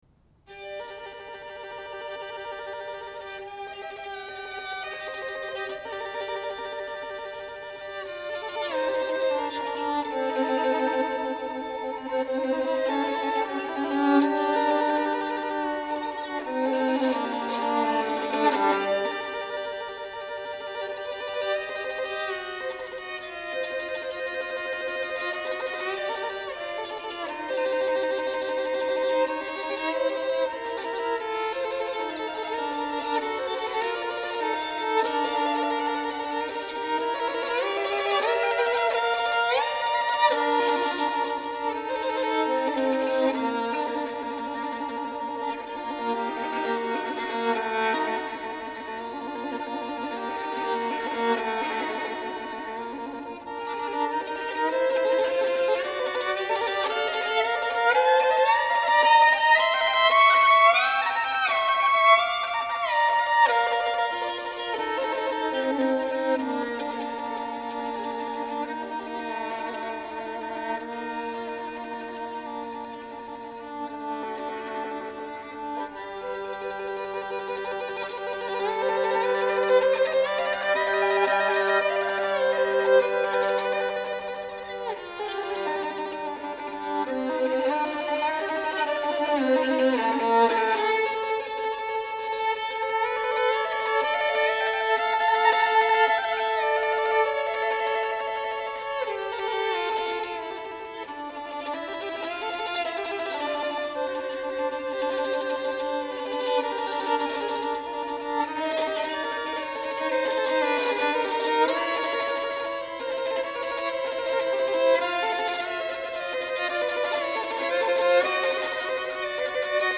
для скрипки соло